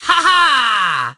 penny_start_vo_03.ogg